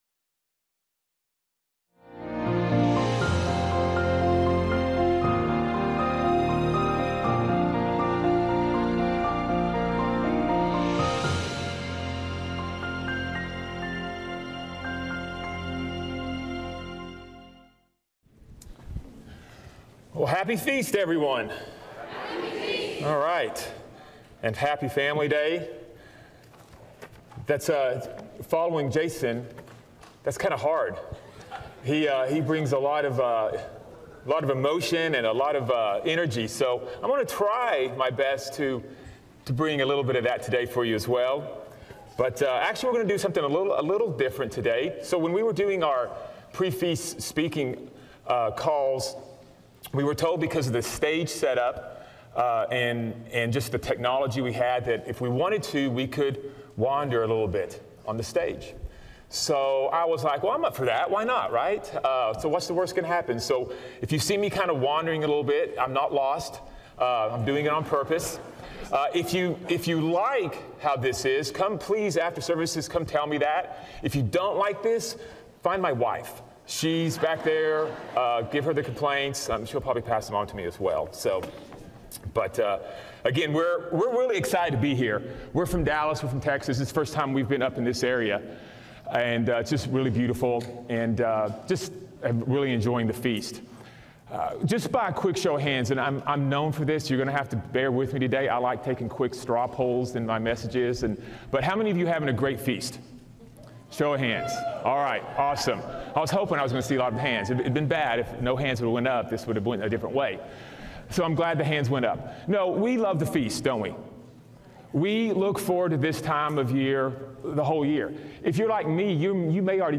This sermon was given at the Spokane Valley, Washington 2023 Feast site.